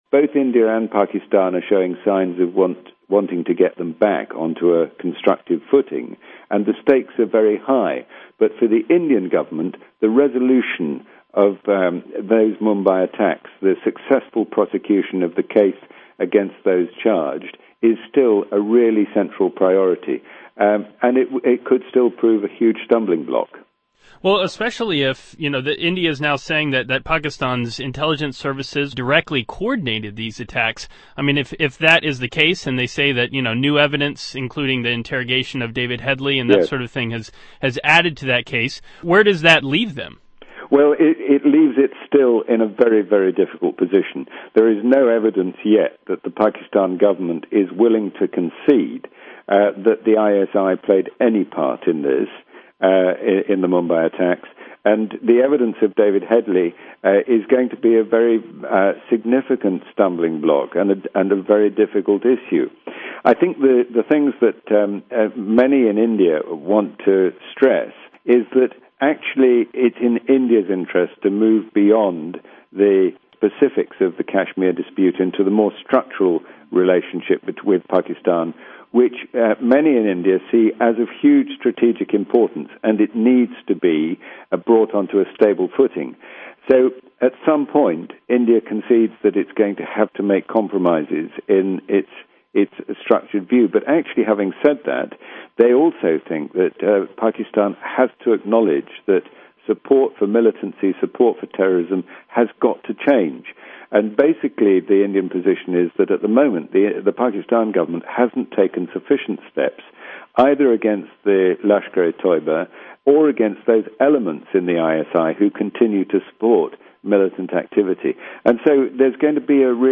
Listen to an interview with analyst